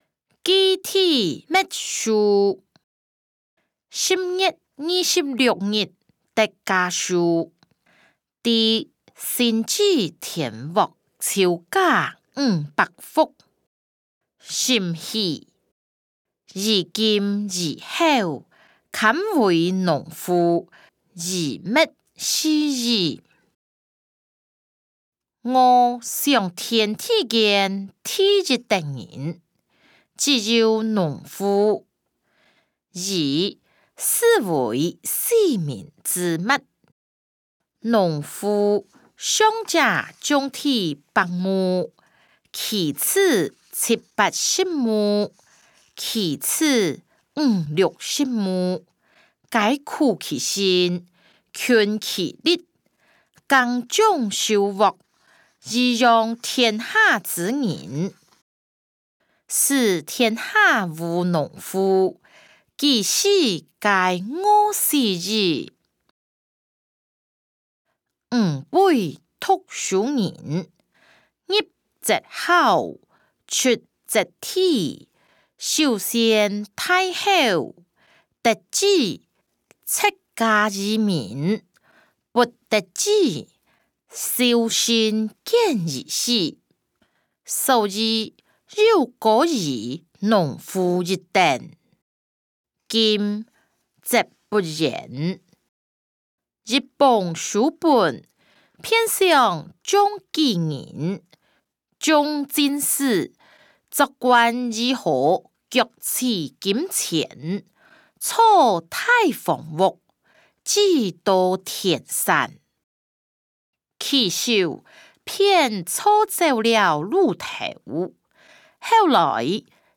歷代散文-寄弟墨書音檔(大埔腔)